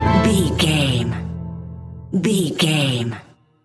Aeolian/Minor
accordion
tension
ominous
dark
suspense
haunting
creepy
spooky